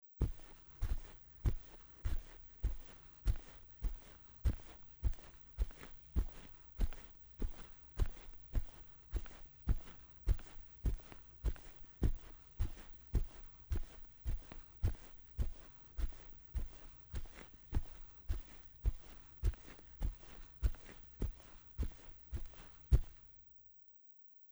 土路上的行走－YS070525.mp3
通用动作/01人物/01移动状态/土路/土路上的行走－YS070525.mp3
• 声道 立體聲 (2ch)